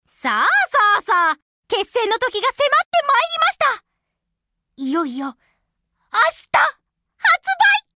「tan.-タンジェント-」発売日前日ボイス　-やや編-